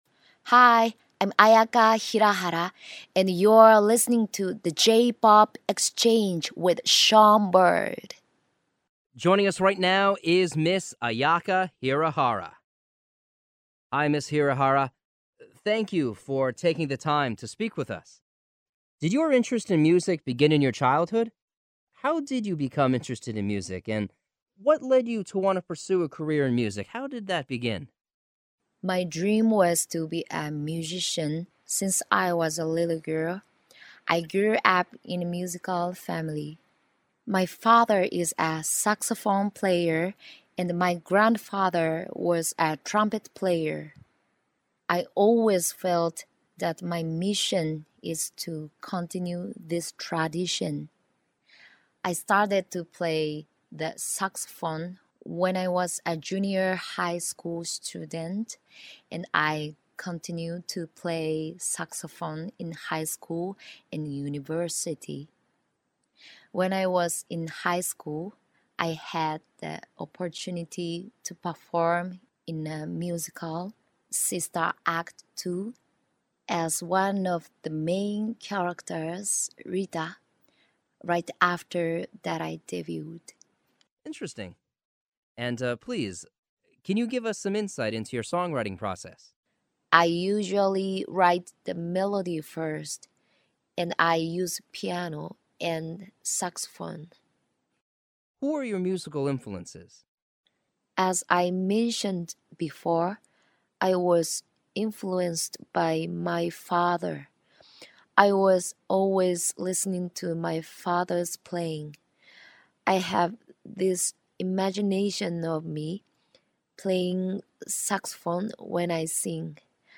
The_JPop_Exchange_Exclusive_Interview_With_Ayaka_Hirahara2.mp3